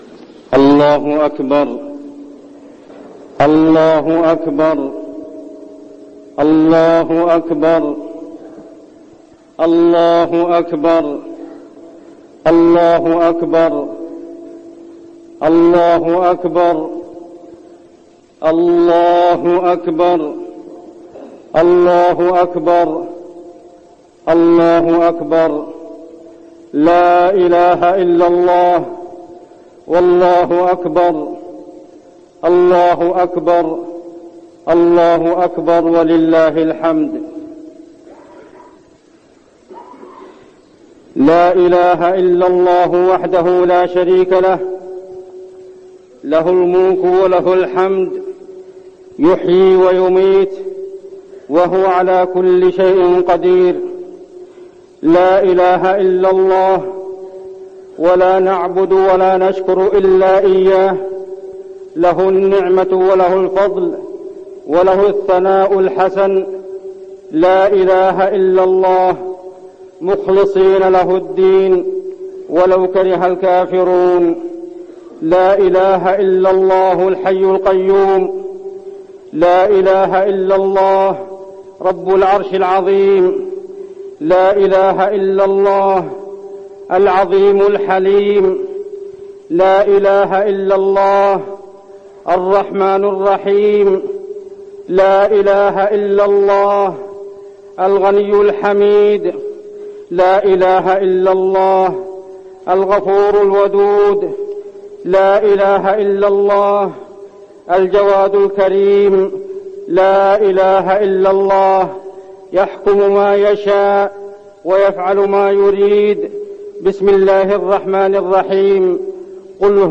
خطبة الاستسقاء - المدينة- الشيخ عبدالله الزاحم - الموقع الرسمي لرئاسة الشؤون الدينية بالمسجد النبوي والمسجد الحرام
تاريخ النشر ٨ جمادى الأولى ١٤٠٨ هـ المكان: المسجد النبوي الشيخ: عبدالله بن محمد الزاحم عبدالله بن محمد الزاحم خطبة الاستسقاء - المدينة- الشيخ عبدالله الزاحم